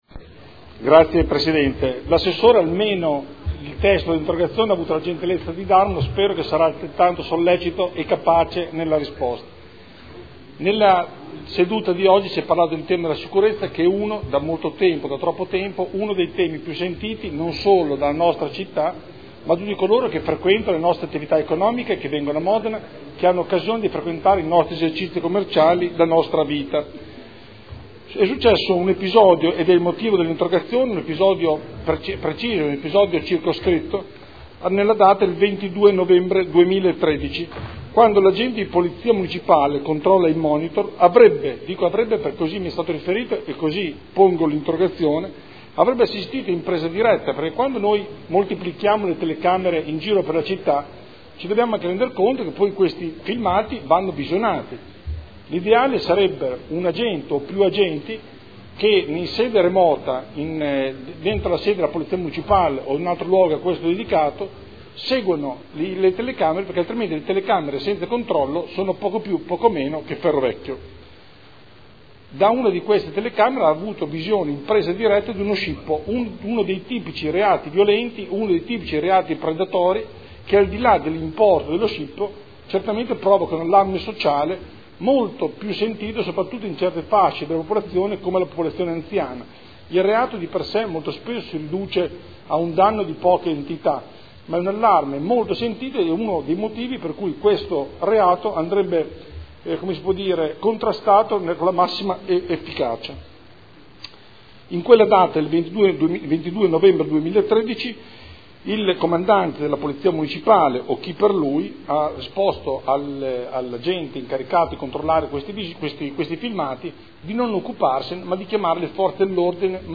Andrea Galli — Sito Audio Consiglio Comunale